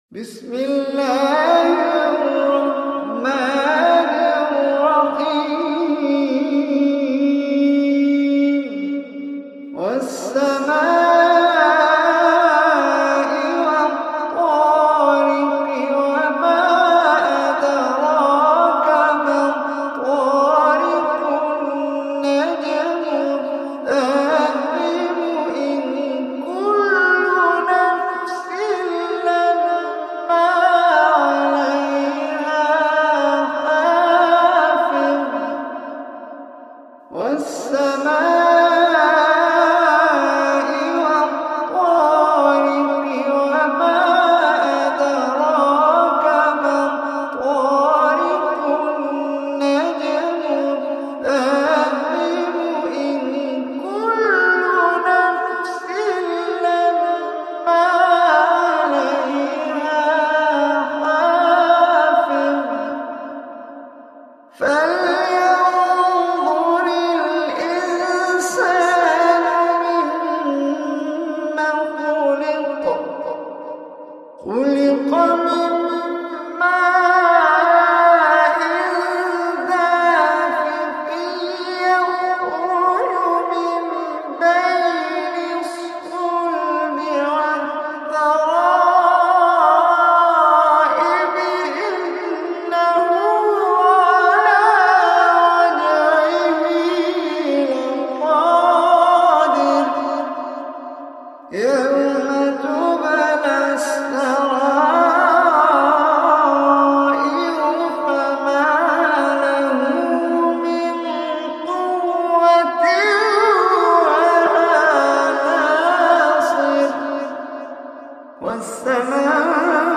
Surah Tariq Recitation by Omar Hisham Arabi